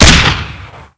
sol_reklam_link sag_reklam_link Warrock Oyun Dosyalar� Ana Sayfa > Sound > Weapons > MAC10 Dosya Ad� Boyutu Son D�zenleme ..
WR_Fire.wav